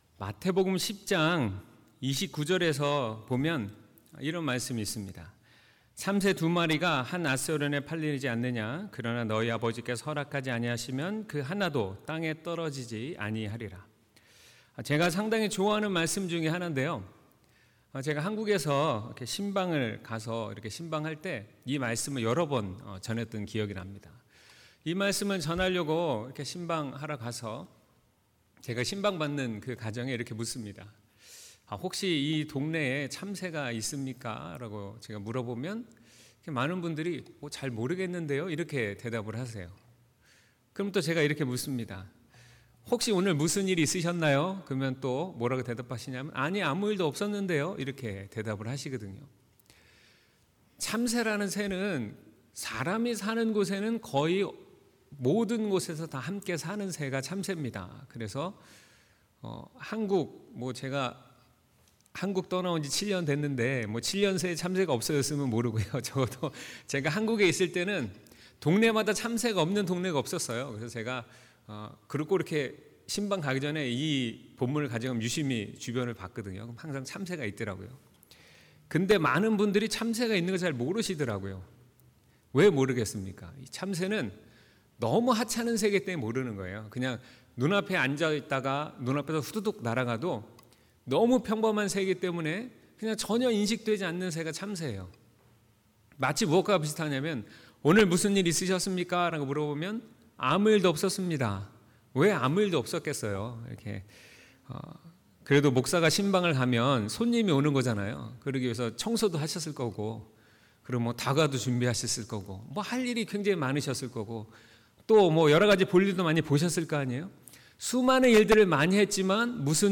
2019년 6월 16일 주일 설교/섭리와 인도/ 출3:7-8